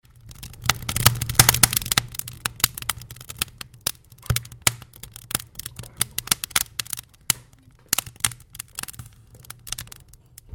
Fire